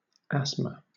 wymowa:
bryt. (RP) IPA/ˈæsmə/
amer. IPA/ˈæzmə/